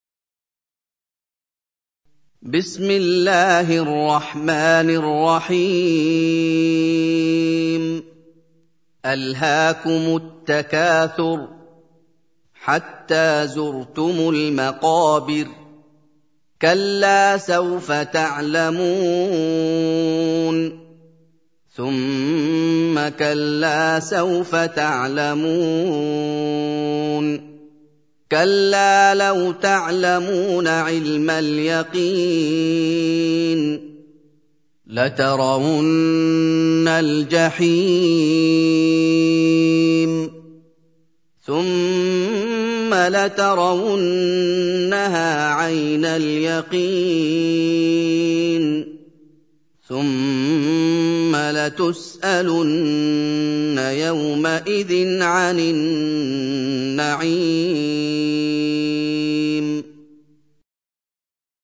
102. Surah At-Tak�thur سورة التكاثر Audio Quran Tarteel Recitation
Surah Sequence تتابع السورة Download Surah حمّل السورة Reciting Murattalah Audio for 102.